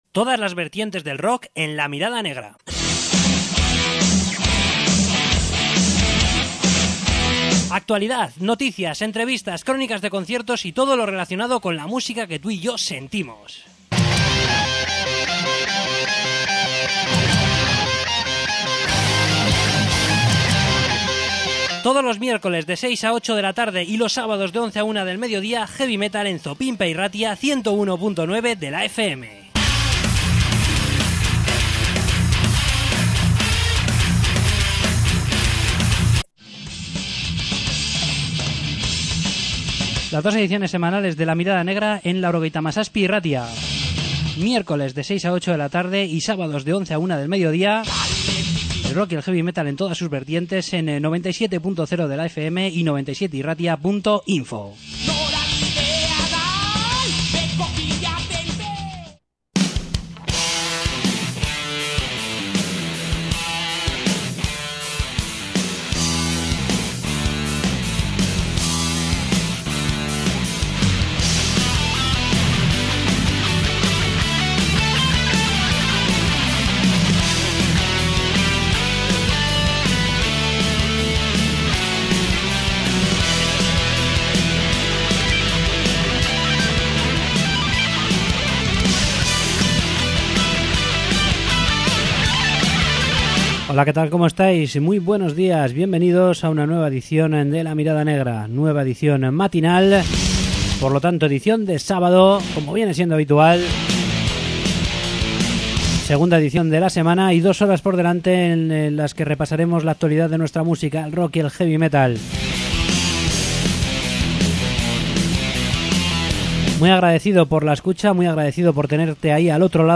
Entrevista con Icestorm